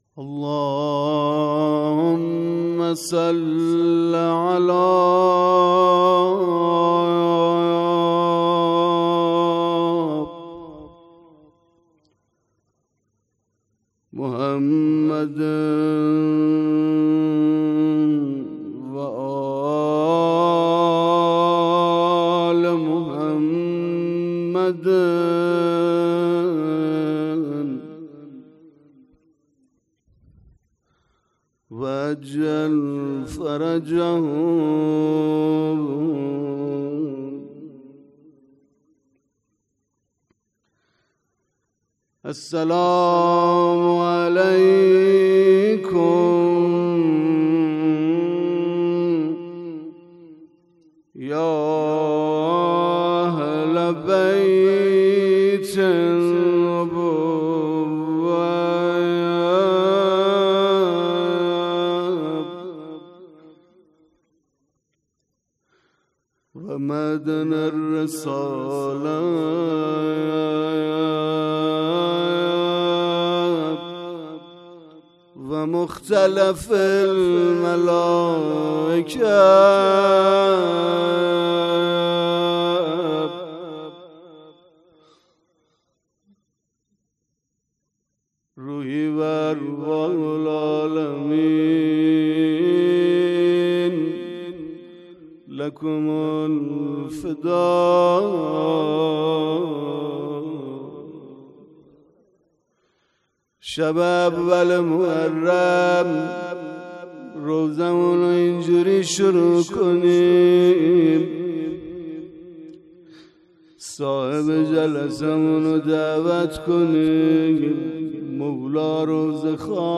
مراسم عزاداری محرم ۱۴۰۴